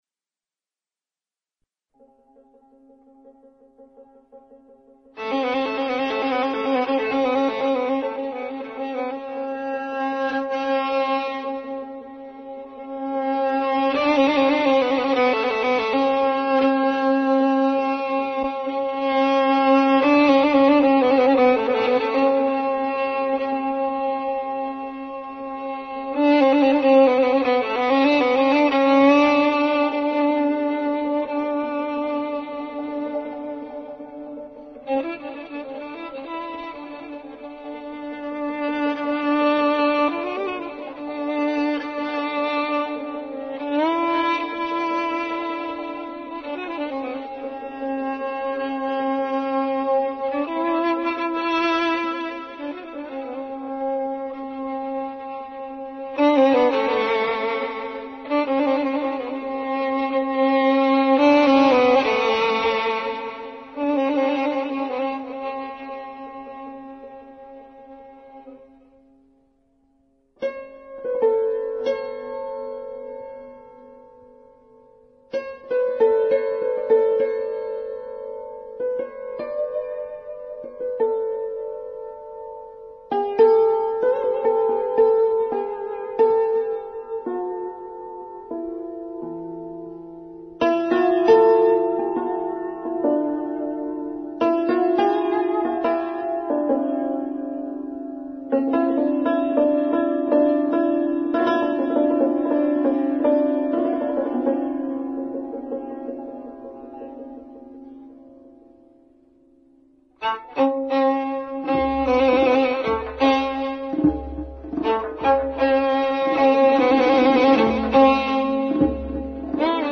ویلن
سنتور
در سه گاه